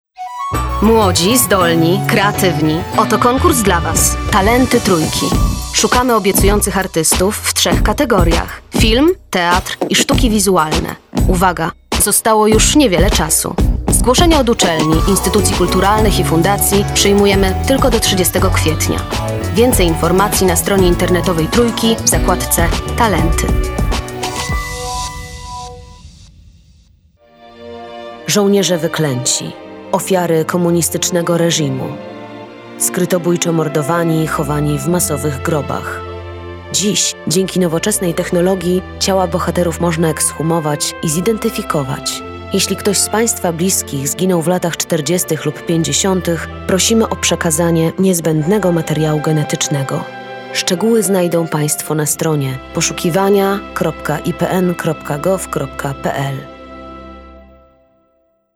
Kobieta 20-30 lat
Nagranie lektorskie